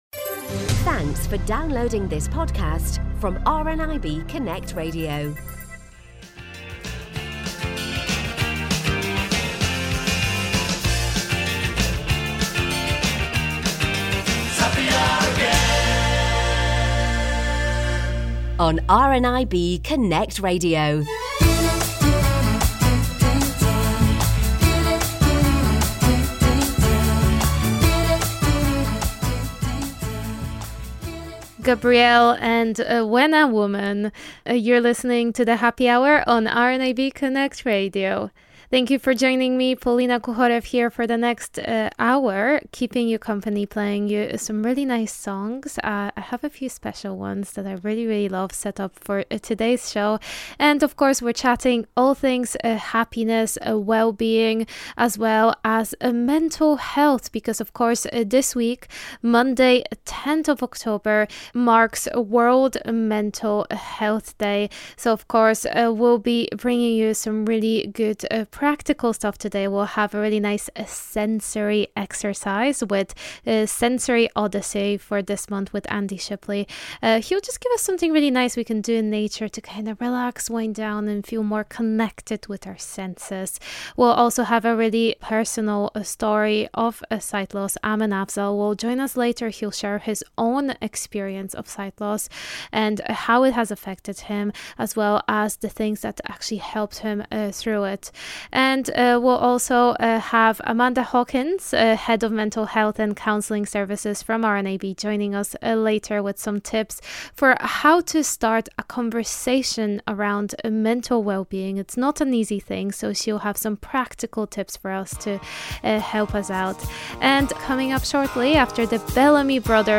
The Happy Hour is here with great music and all things sight loss, wellbeing and happiness.